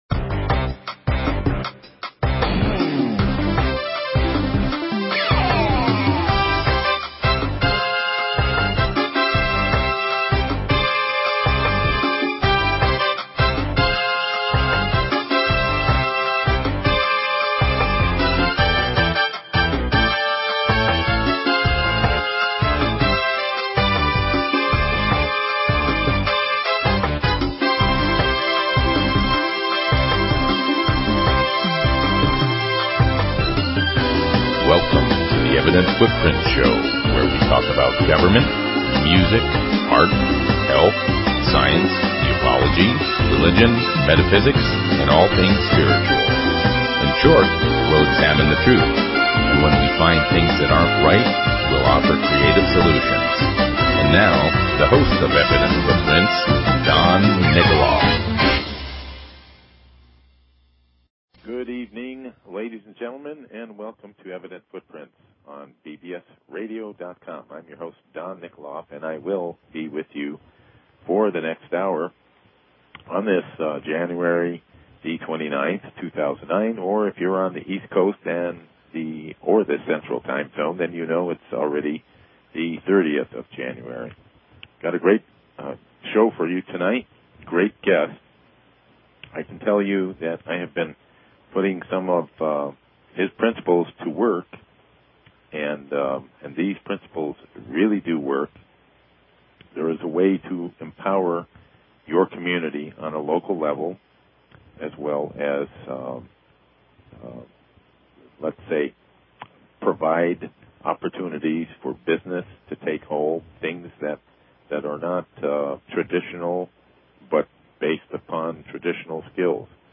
Talk Show Episode, Audio Podcast, Evident_Footprints and Courtesy of BBS Radio on , show guests , about , categorized as
Civil Rights/Antitrust Attorney